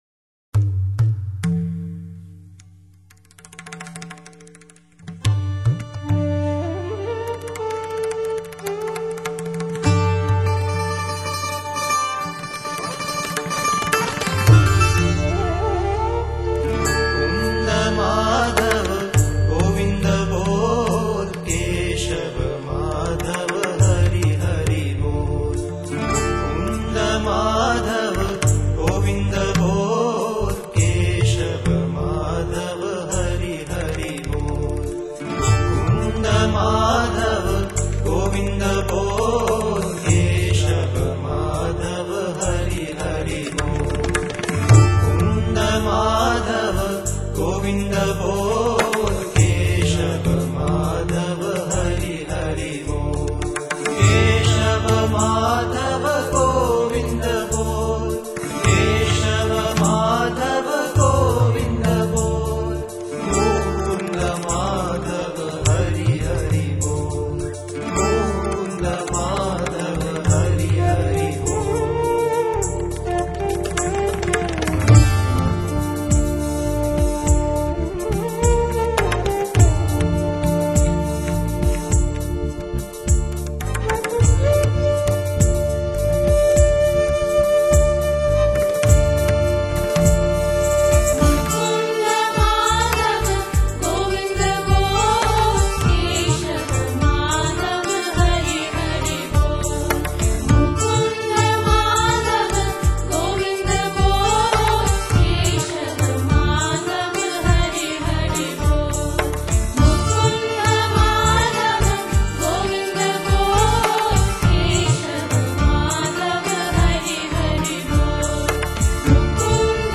瑜伽灵性音乐
结合现代感节奏，使古老的吟唱更加充满生命力。